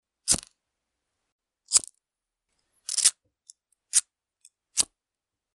На этой странице собраны разнообразные звуки зажигалок: от металлических щелчков до гула пламени.
Звук кремниевой зажигалки